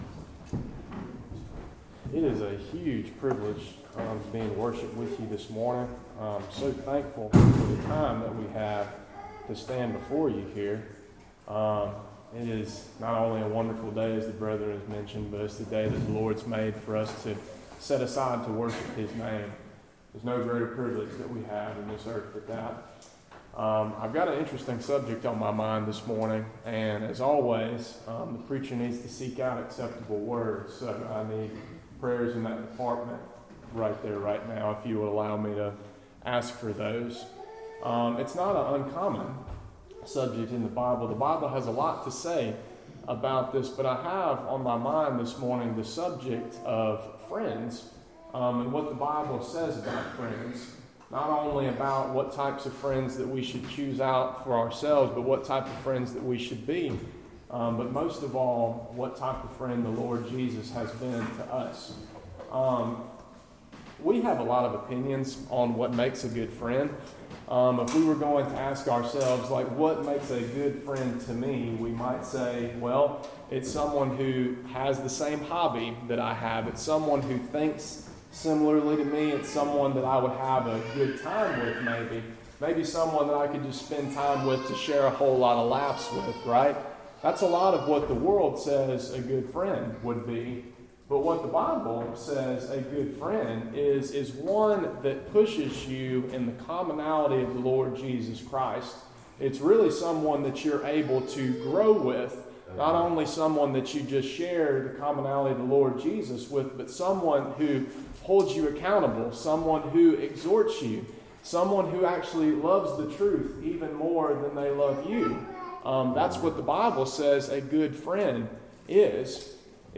Topic: Sermons